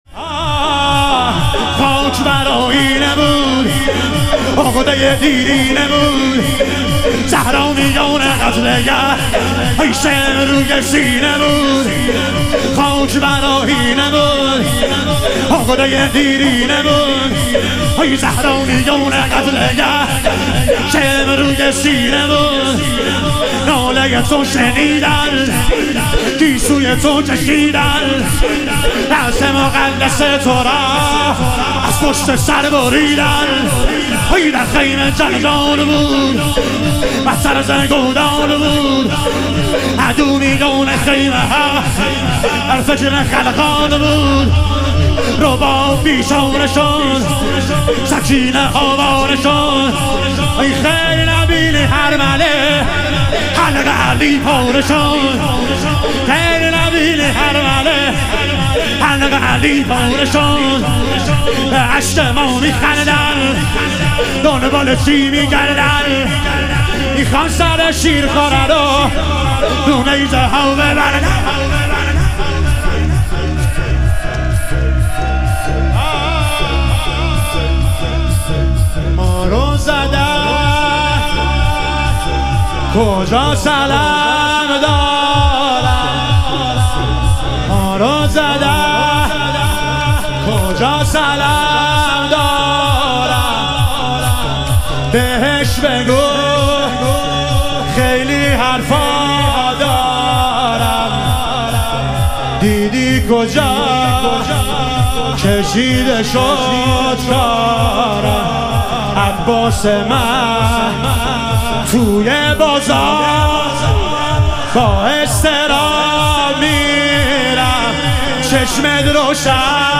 شهادت حضرت زینب کبری علیها سلام - لطمه زنی